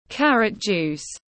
Nước ép cà rốt tiếng anh gọi là carrot juice, phiên âm tiếng anh đọc là /ˈkær.ət ˌdʒuːs/
Carrot juice /ˈkær.ət ˌdʒuːs/